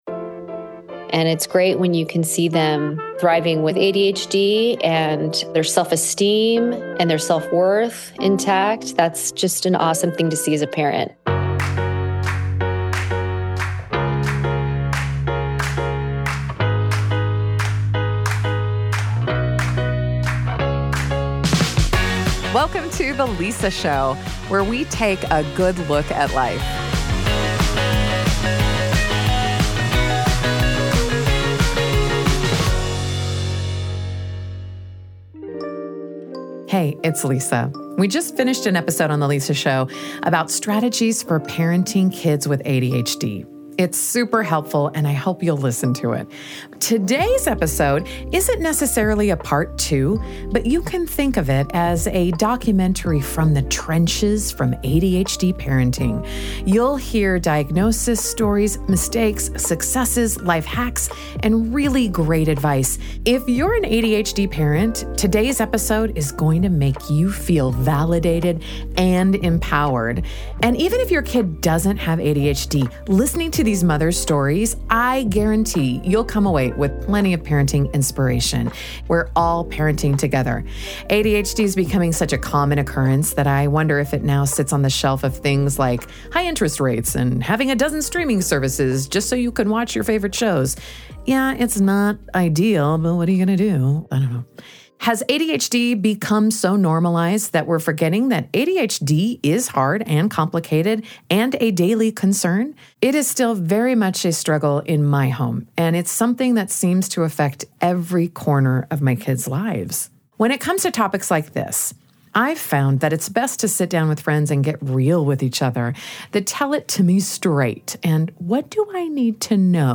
In this episode, three moms open up about their journeys raising children with ADHD. Through honest stories, hard-won lessons, and heartfelt advice, they offer hope and reassurance to any parent wondering if they’re doing enough.